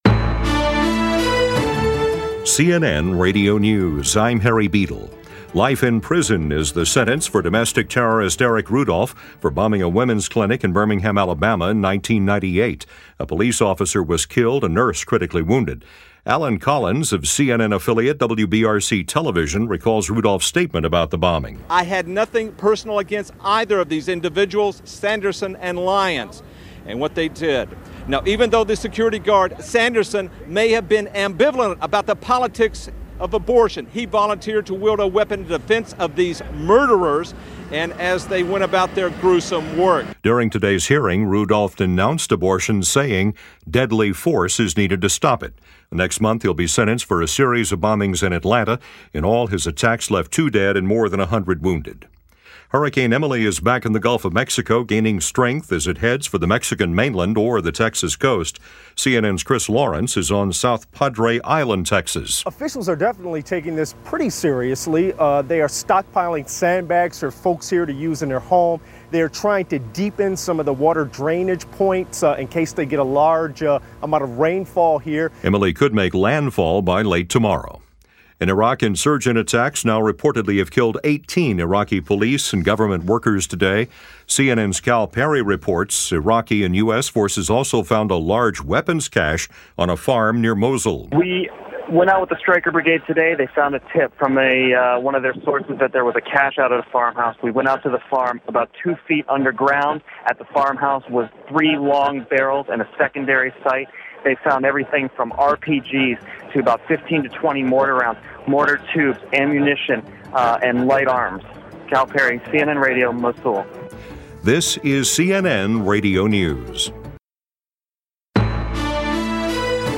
And that’s what happened on this July 18, 2005 as presented by CNN Radio News.